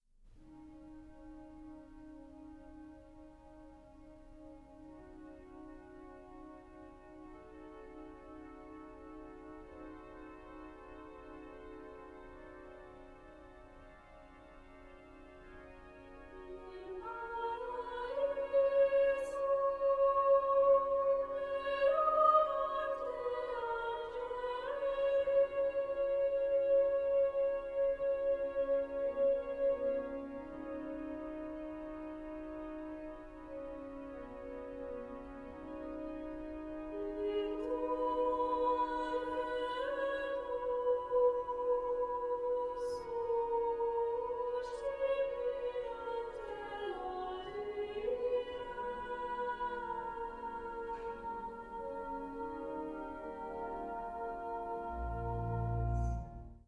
soprano
cello
organ